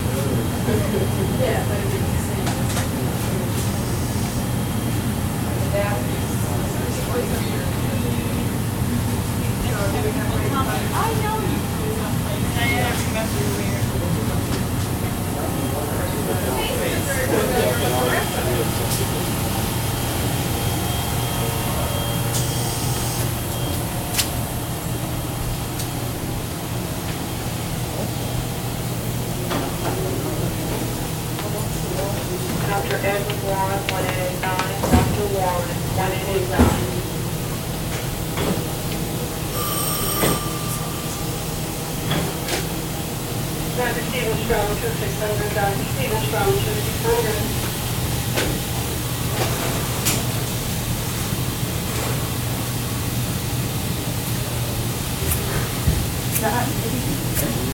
Hospital Hallway Loop With AC, Walla, Announcements